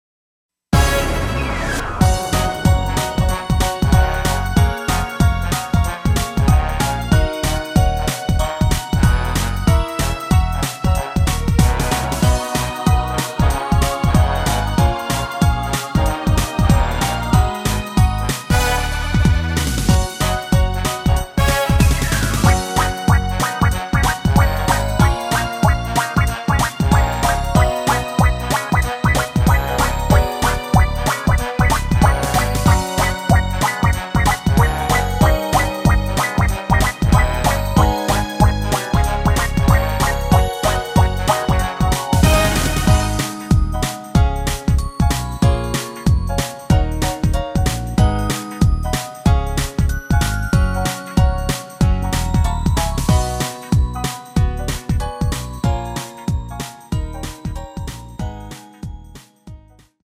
대부분의 남성분이 부르실수 있는 키로 제작 하였습니다 ~
F#
앞부분30초, 뒷부분30초씩 편집해서 올려 드리고 있습니다.
중간에 음이 끈어지고 다시 나오는 이유는